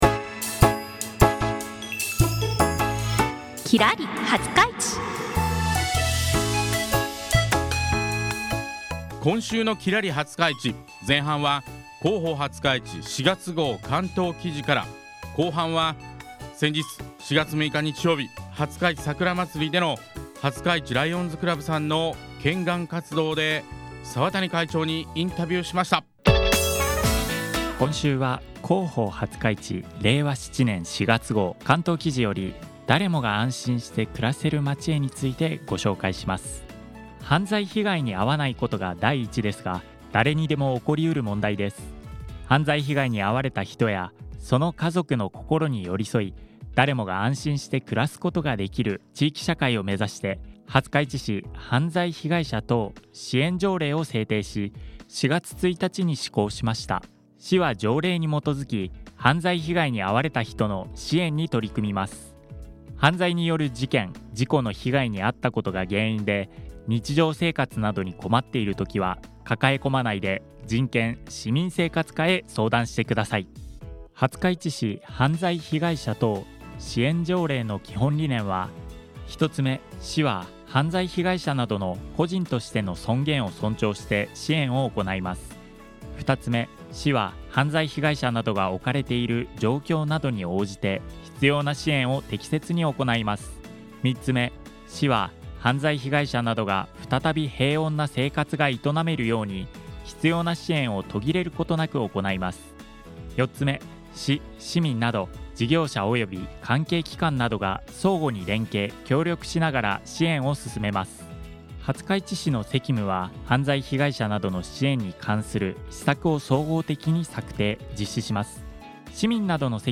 「きらり☆はつかいち」 廿日市の〝今〟をきらりと輝く2組の方にインタビュー！